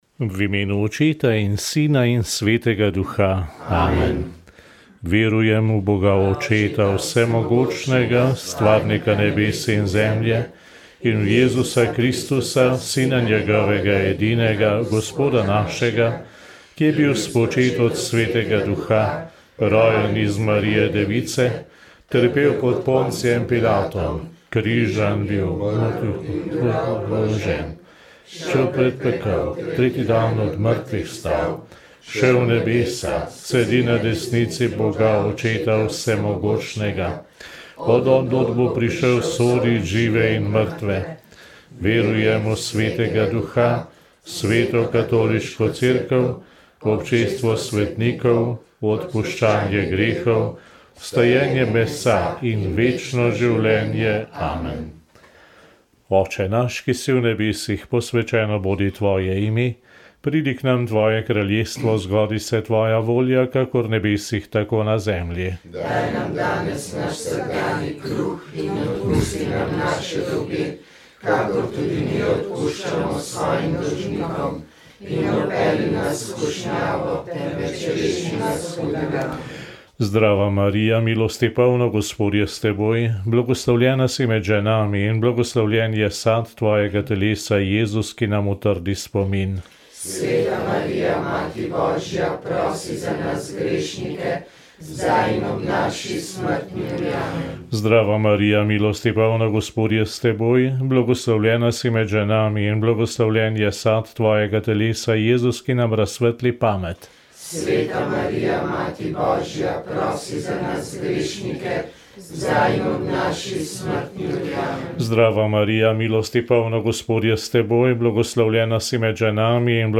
2. aprila 2025 je v prostorih mestne občine Nova Gorica potekala Konferenca za trajni mir med narodoma in pokop mrtvih, ki jo je pripravila Civilna iniciativa TBH. Na konferenci so predstavili pobudo za pokop žrtev povojnih pobojev na Trnovski in Banjški planoti. O dogajanju med drugo svetovno vojno in po njej na območju Goriške so govorili slovenski in italijanski zgodovinarji.